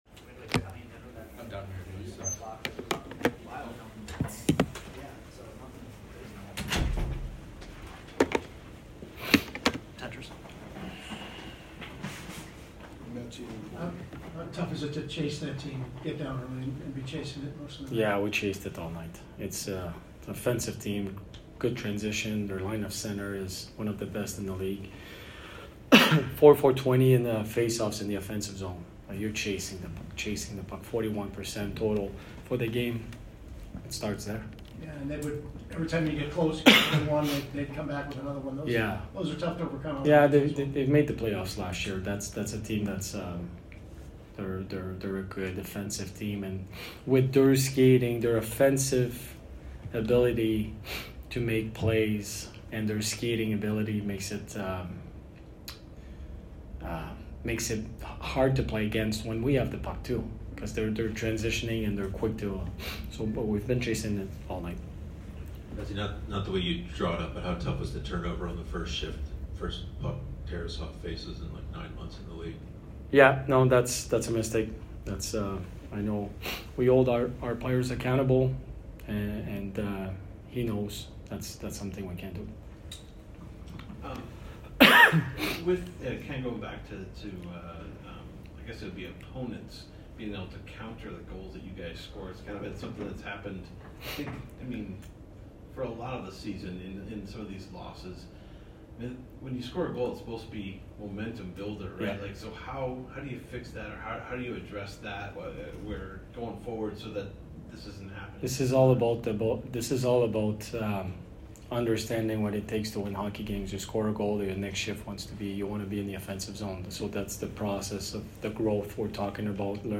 BLUE JACKETS POST-GAME AUDIO INTERVIEWS